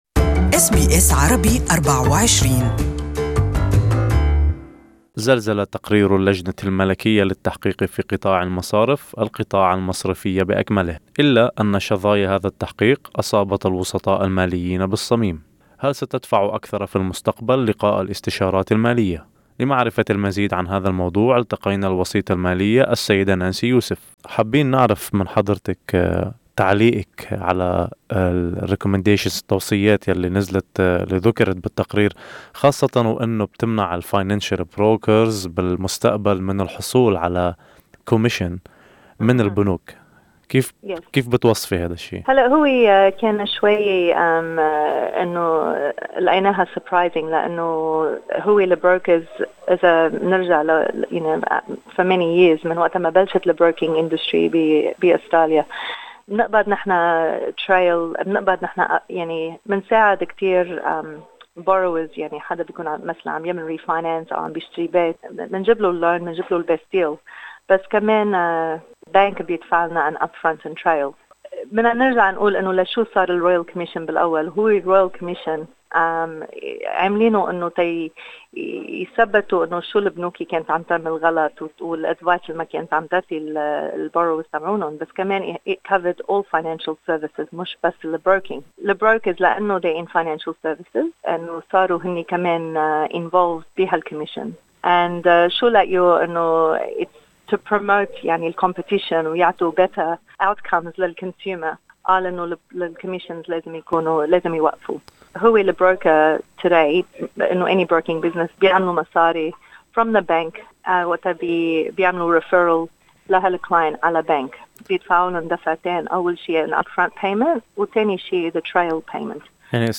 Audio interview in Arabic